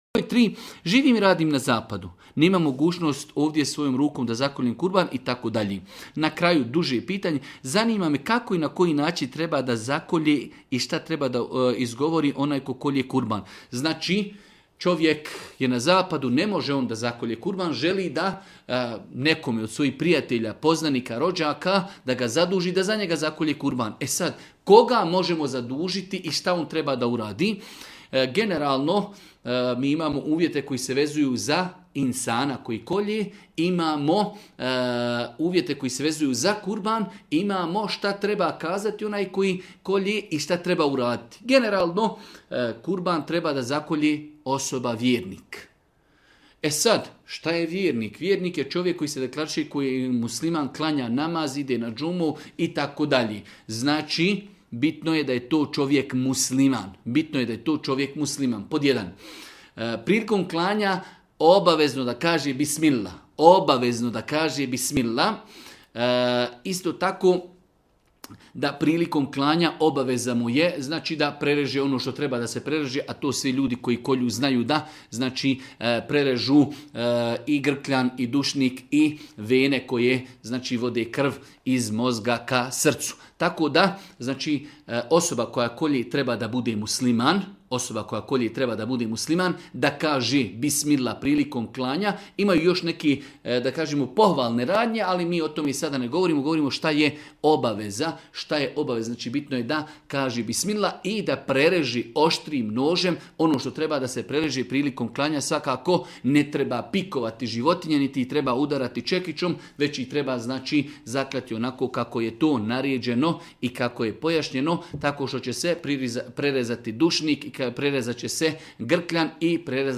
u video predavanju